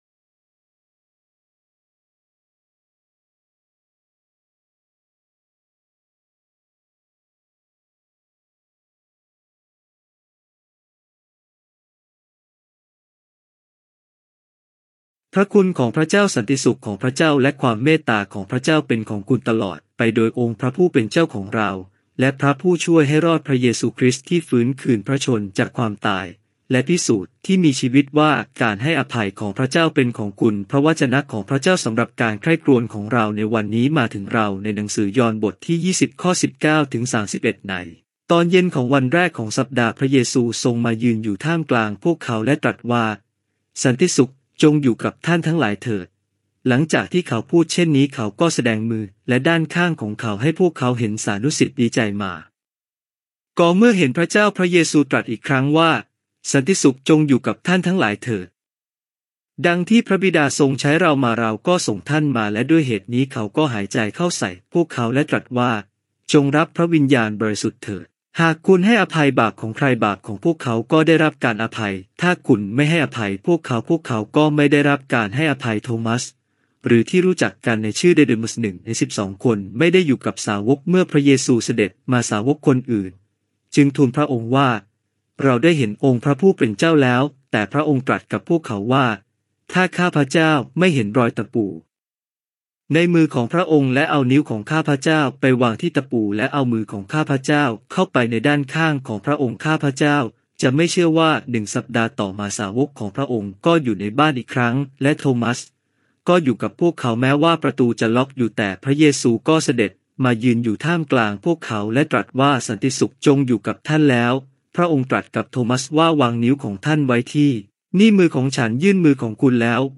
Weekly Sermon เทศน์ประจำสัปดาห์
First week after Easter - สัปดาห์แรกหลังเทศกาลอีสเตอร์